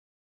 sfx-silence.ogg